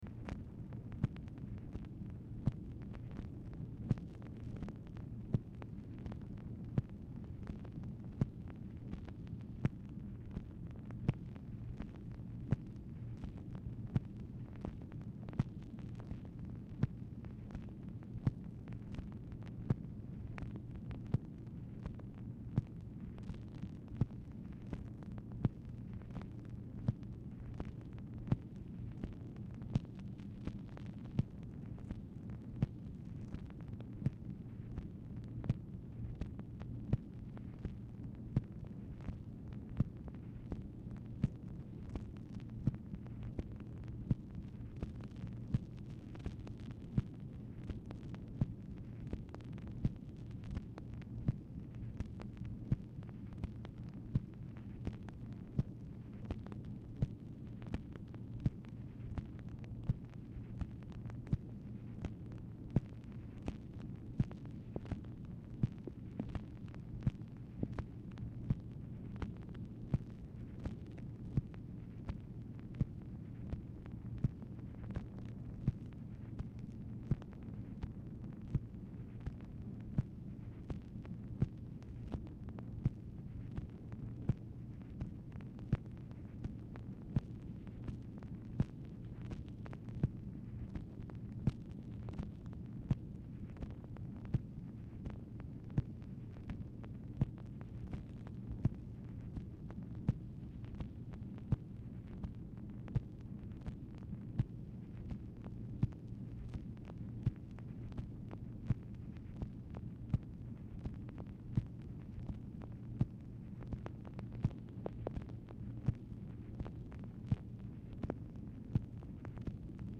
Telephone conversation # 9835, sound recording, MACHINE NOISE, 3/4/1966, time unknown | Discover LBJ
Format Dictation belt
Specific Item Type Telephone conversation